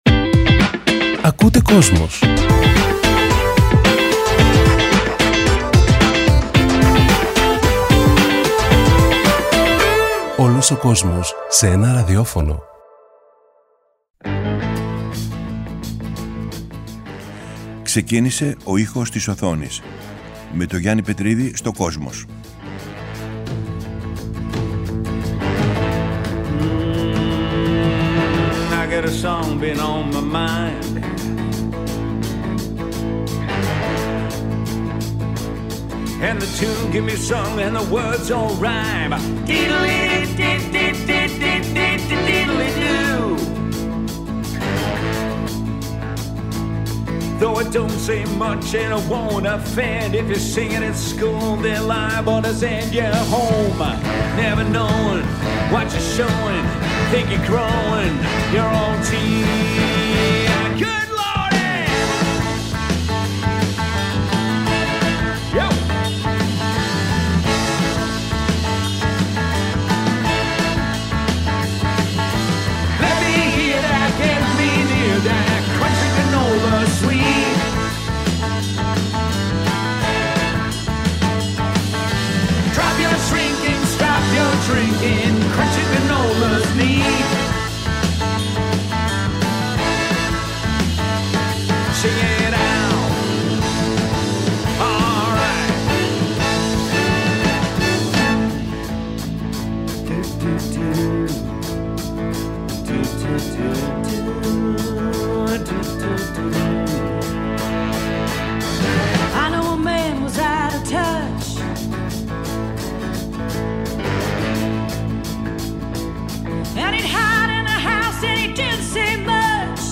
Από την Κυριακή 3 Δεκεμβρίου 2018 ξεκίνησε το αφιέρωμα του Γιάννη Πετρίδη στο Kosmos 93,6, με μουσική και τραγούδια που ξεκίνησαν την καριέρα τους από τον κινηματογράφο και, σε ένα δεύτερο στάδιο, από την τηλεόραση.